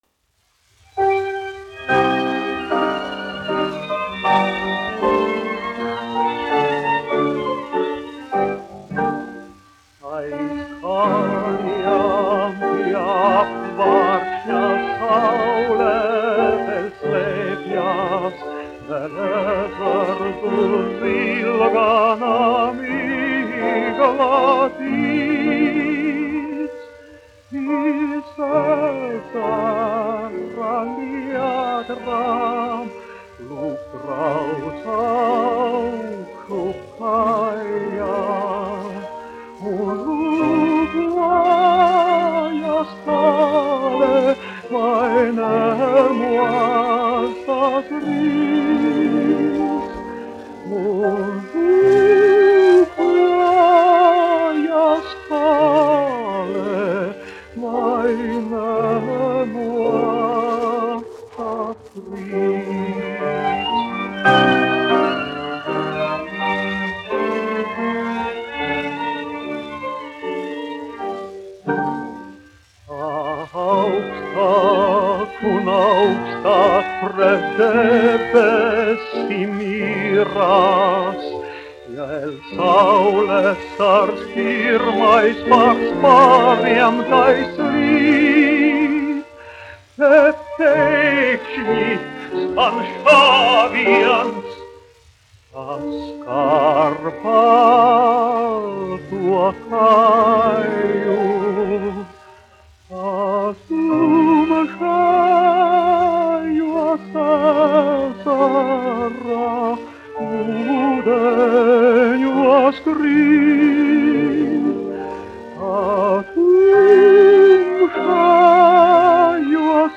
1 skpl. : analogs, 78 apgr/min, mono ; 25 cm
Dziesmas (augsta balss) ar instrumentālu ansambli
Latvijas vēsturiskie šellaka skaņuplašu ieraksti (Kolekcija)